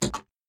remote_click.mp3